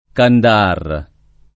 Candahar [ kanda- # r ] → Qandahār